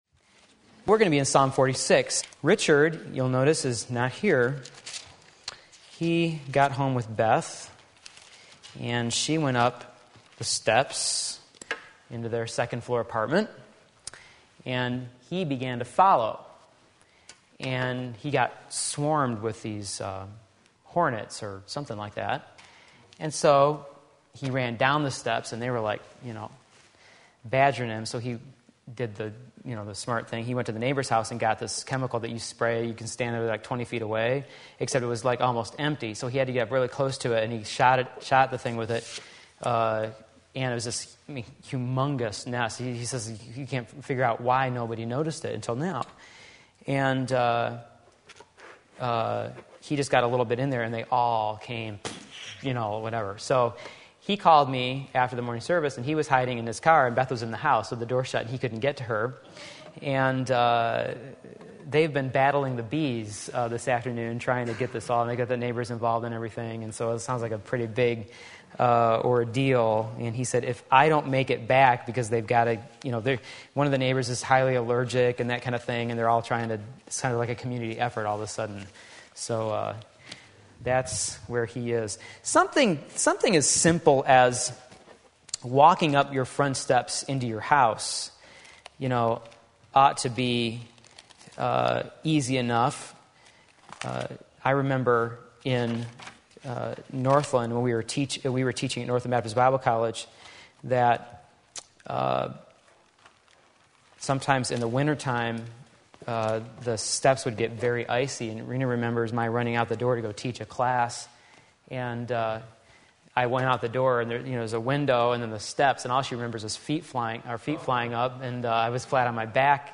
Part 1 Psalm 46 Sunday Afternoon Service